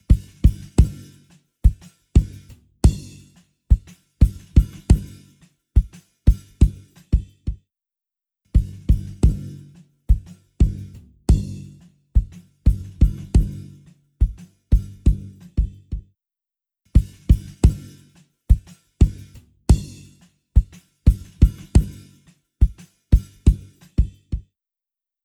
インパクトを犠牲にすることなく、低域の濁りを素早く簡単に除去。大掛かりで扱いにくい従来のツールを必要とするオーディオ修復のための、シンプルかつ強力なツール